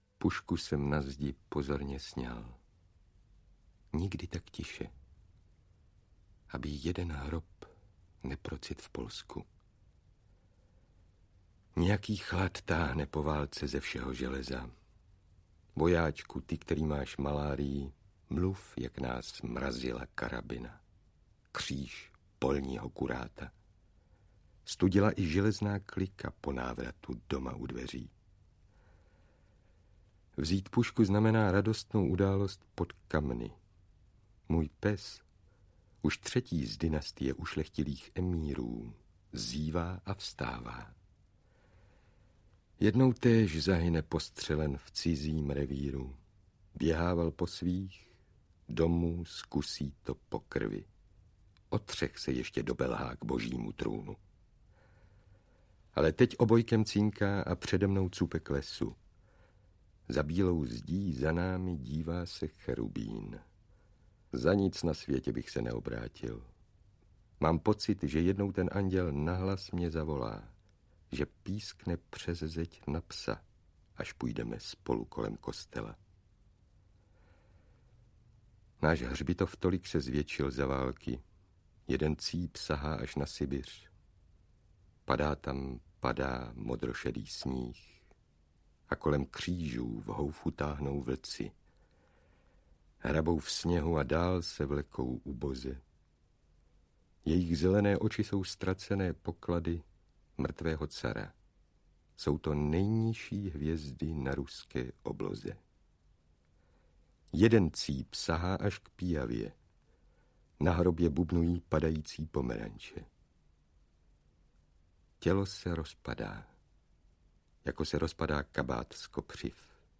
Konstantin Biebl - portrét básníka audiokniha
Ukázka z knihy
• InterpretVěra Kubánková, Hana Maciuchová, Jiří Adamíra, Jiří Němeček, Vladimír Šmeral